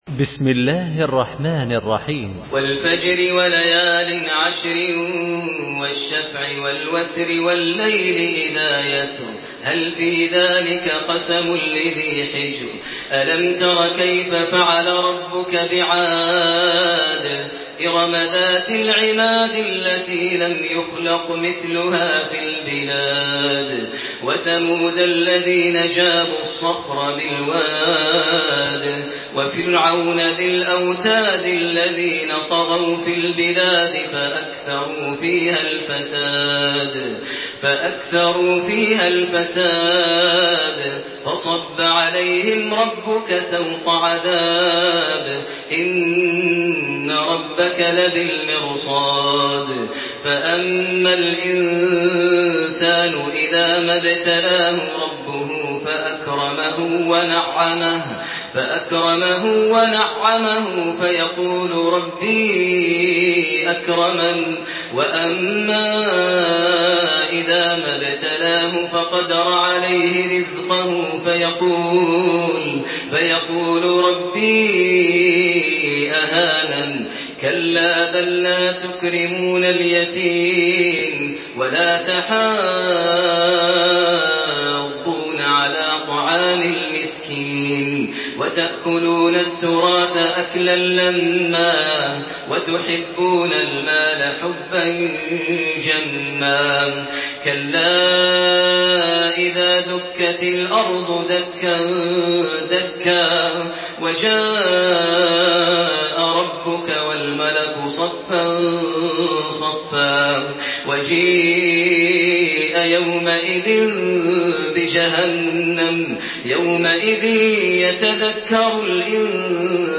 تحميل سورة الفجر بصوت تراويح الحرم المكي 1428
مرتل